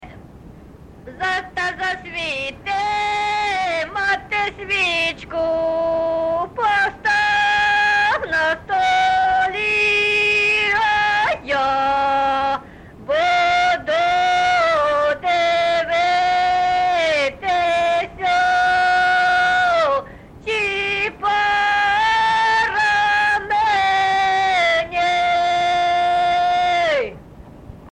ЖанрВесільні
Місце записус. Харківці, Миргородський (Лохвицький) район, Полтавська обл., Україна, Полтавщина